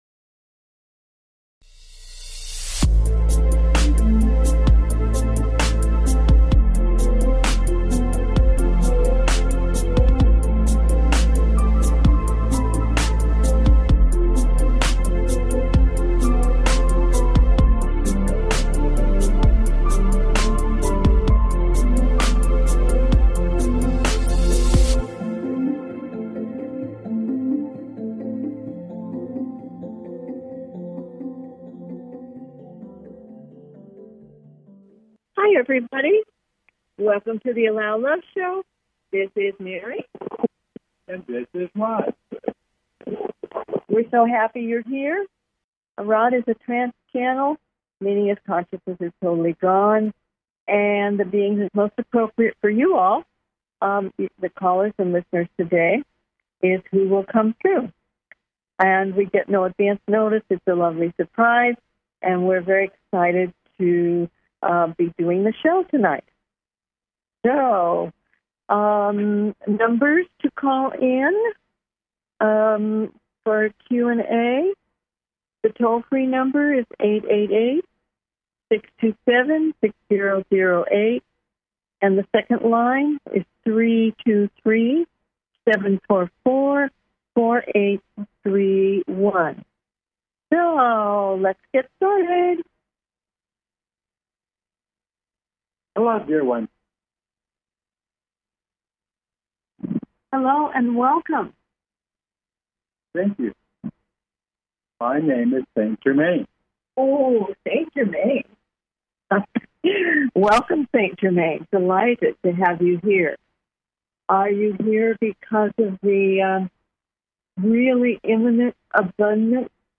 Talk Show Episode, Audio Podcast
Their purpose is to provide answers to callers’ questions and to facilitate advice as callers request.